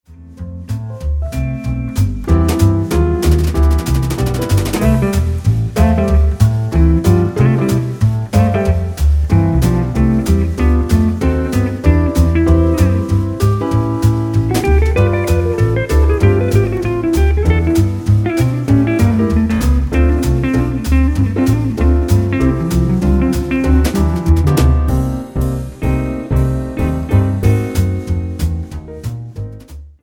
--> MP3 Demo abspielen...
Tonart:C ohne Chor